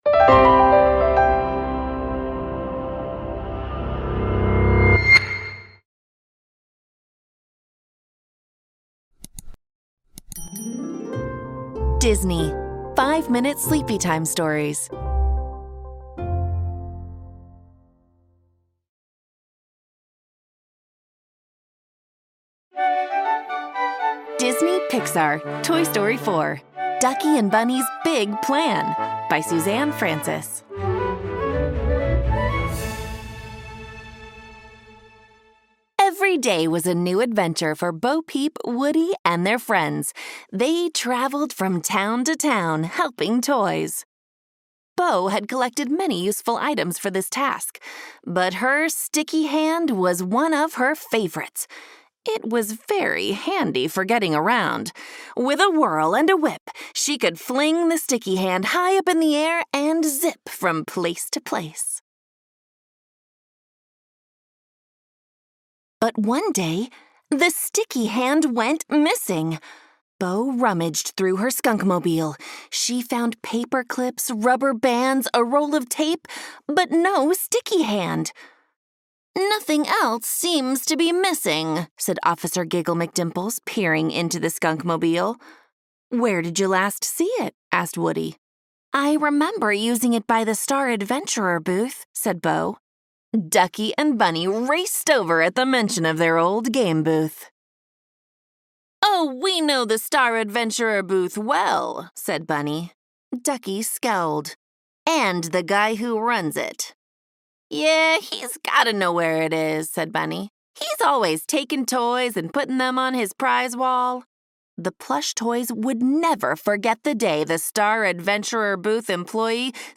The Missing Three-Quarter: Sherlock Holmes Uncovers Deception (Audiobook)